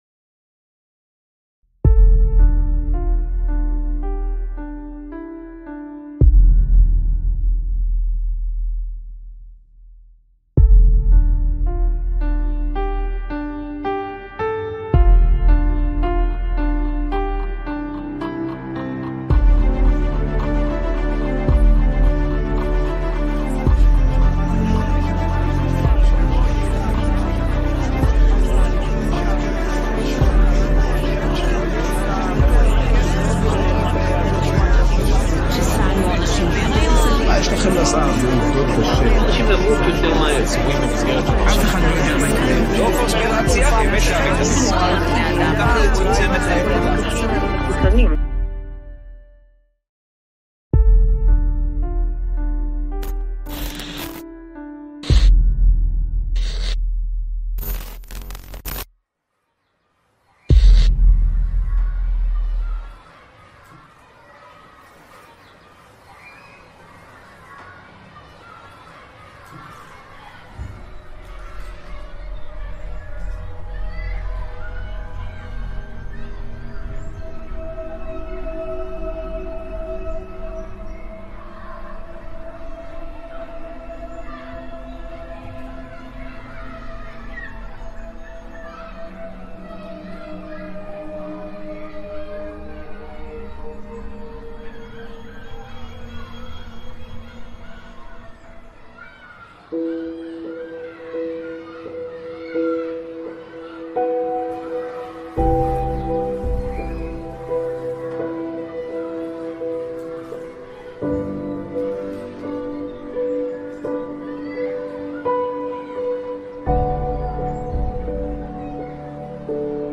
המרואיין מספר שיש עובדים במערכת הבריאות, כולל עיתונאים ורופאים, שבוחרים לא לחסן את ילדיהם לפי לוח החיסונים הרשמי, אך שומרים זאת בסוד מחשש לאבד את עבודתם או להיחשף.